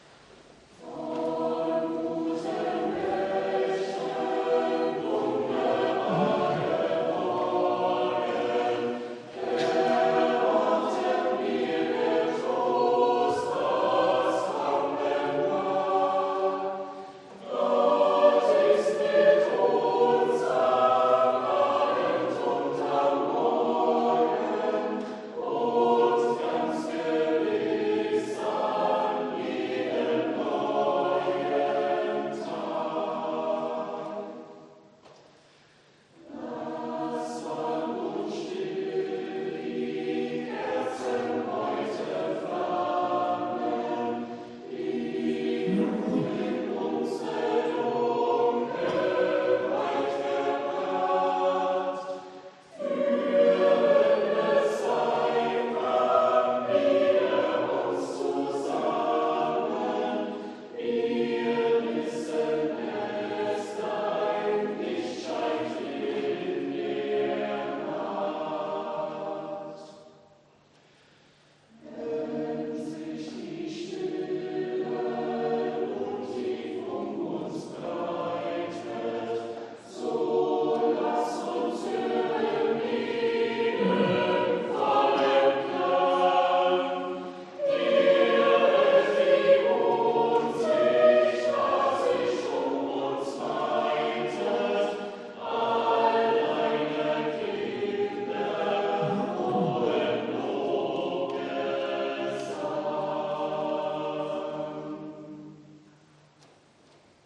Audiomitschnitt unseres Gottesdienstes vom Vorletzten Sonntag im Kirchenjahr 2022.